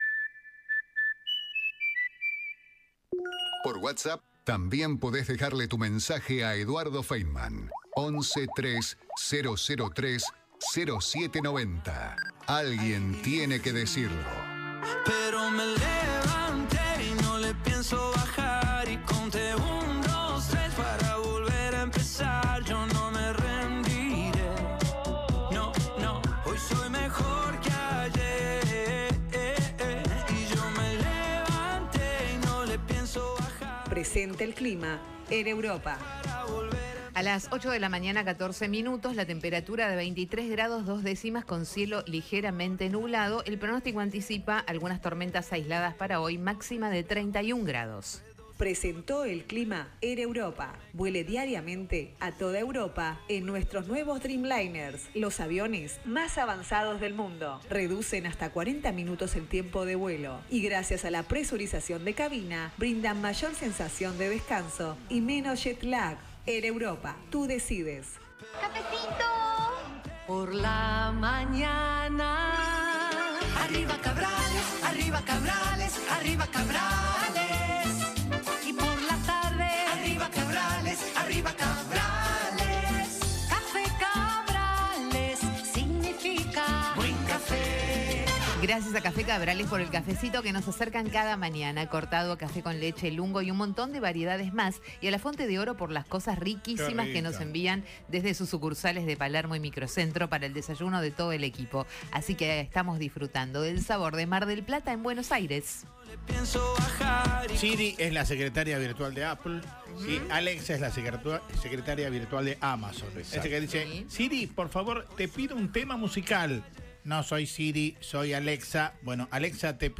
Luis Petri, ministro de Defensa, conversó con Eduardo Feinmann sobre el estado en el cual recibió su cartera y se refirió a la corrupción que hallaron en el ministerio.